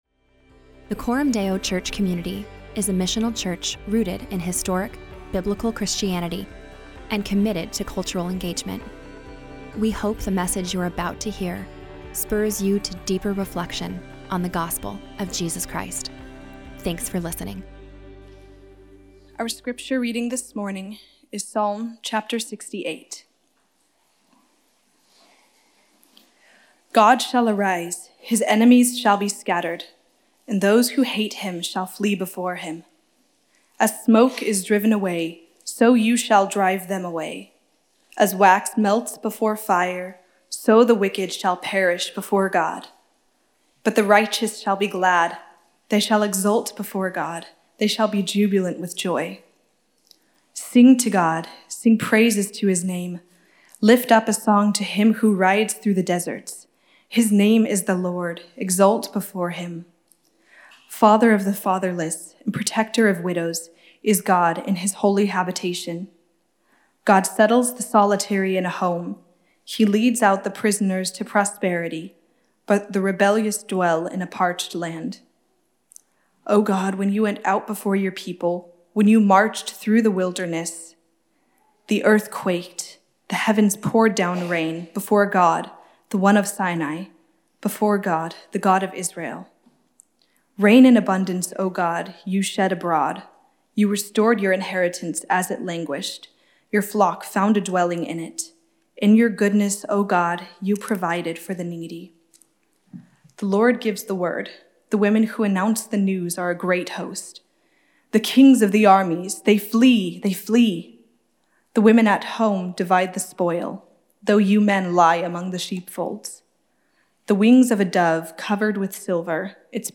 1 You Need a Better Story | Psalm 68 39:54 Play Pause 21d ago 39:54 Play Pause Play later Play later Lists Like Liked 39:54 In this sermon, we explore how God’s work in the past anchors God’s people in the present.